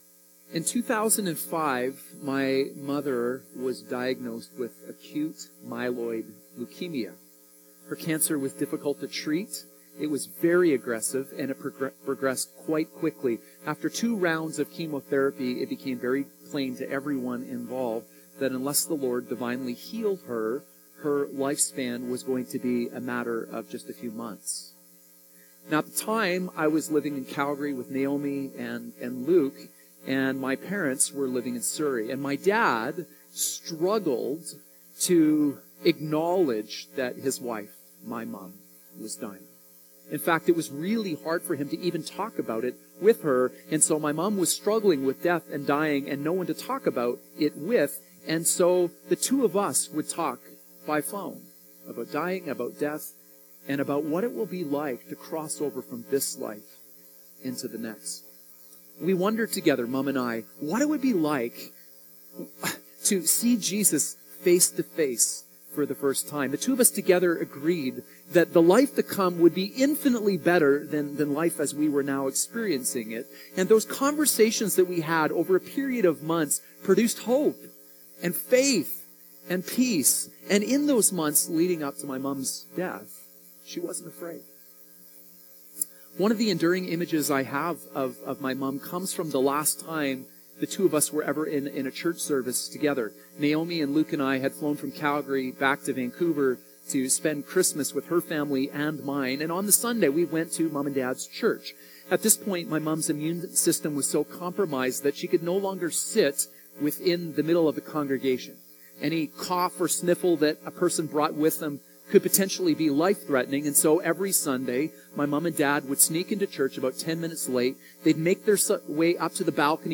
Sermons | North Shore Alliance Church